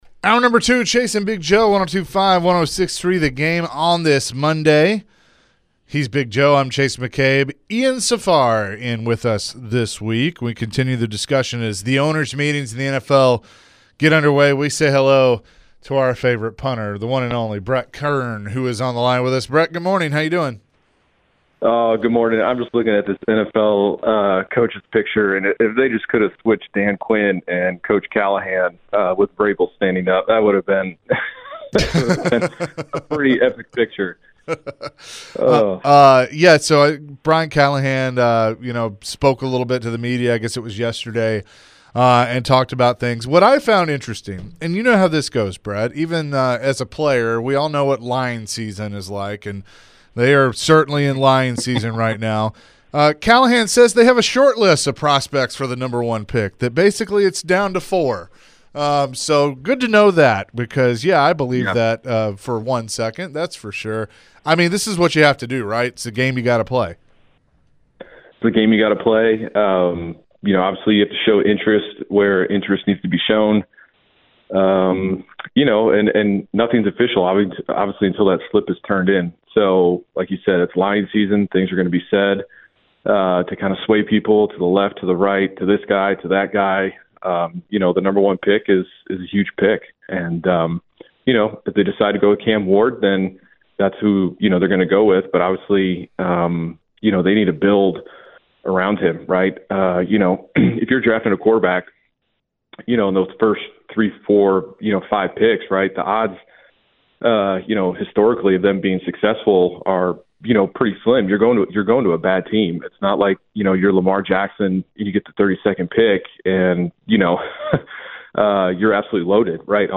Former Titans punter Brett Kern joins the show for his weekly chat talking all things Titans and NFL Draft. What do we make of the latest on the QB carousel, and who are the options left around the league and for the Titans?